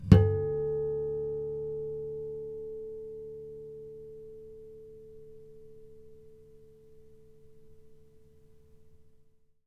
harmonic-10.wav